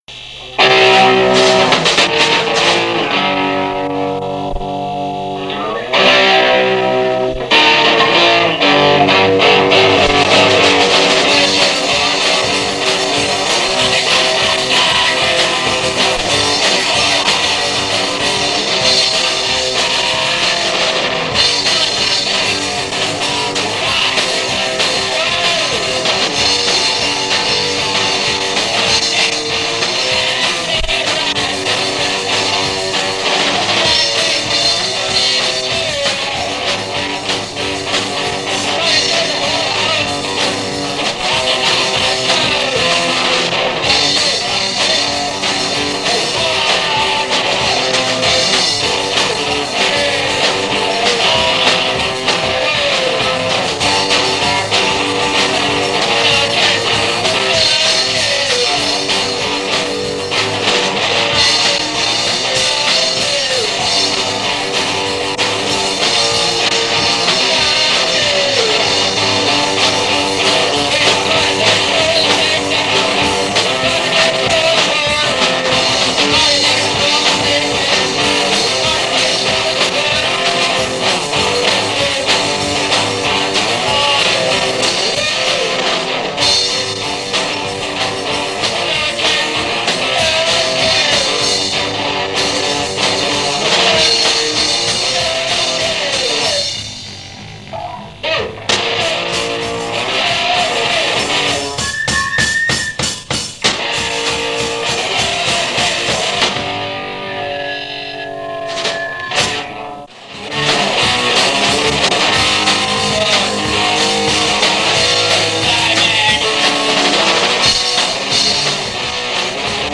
Sången turas dom om med.